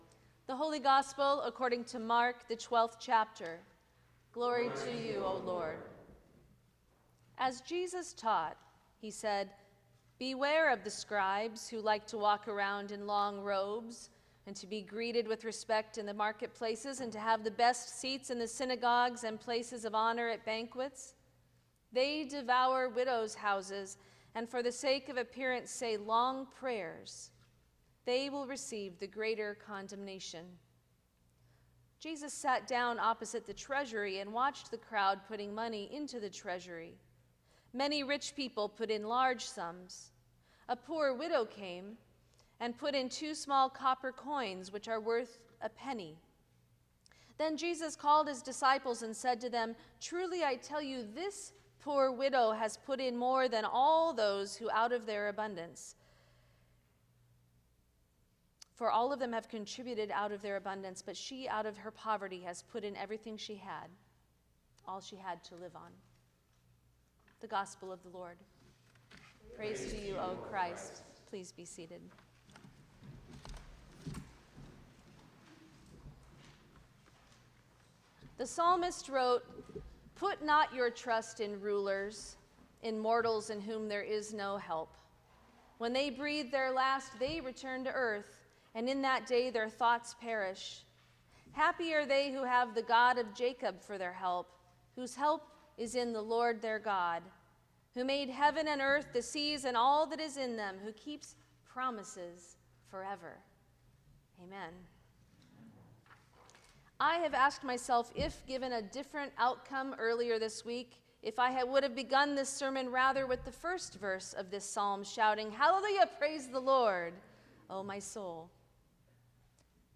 Sermon for the Twenty-Fifth Sunday after Pentecost 2024